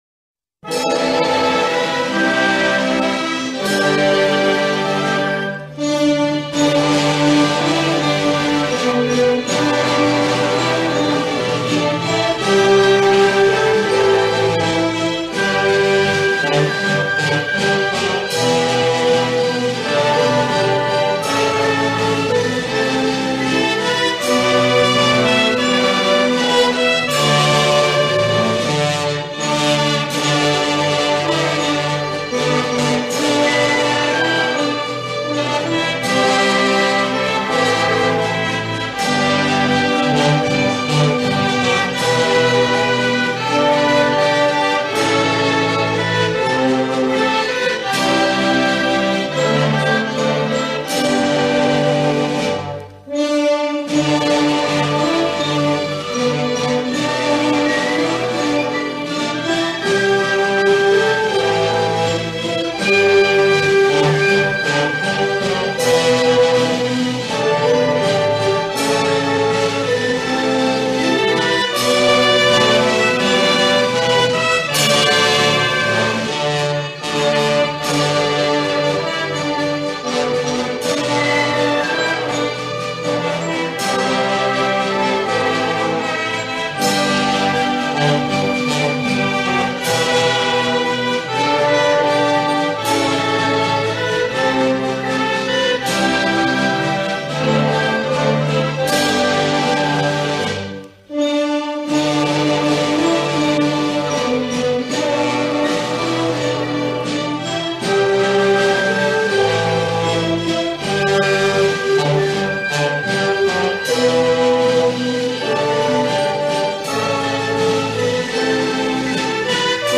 инструментальный